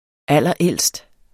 Udtale [ ˈalˀʌˈεlˀsd ]